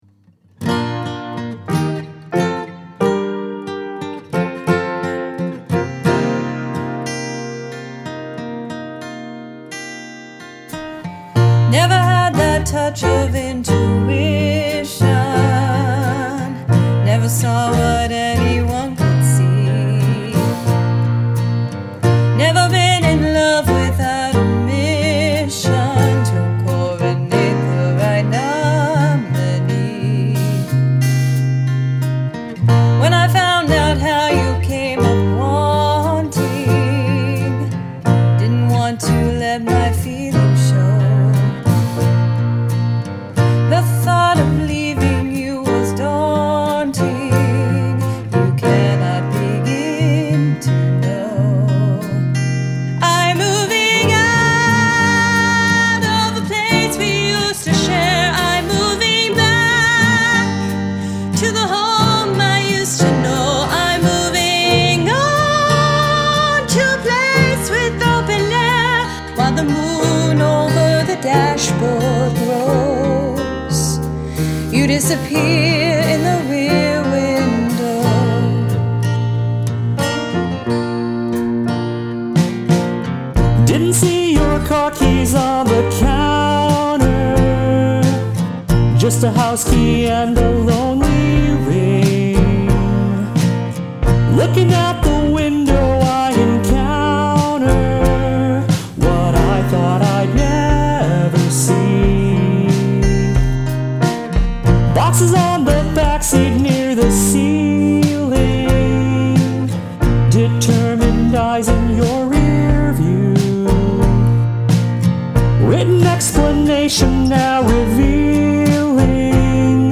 I like the acoustic guitar solo.
First chords are really beautiful.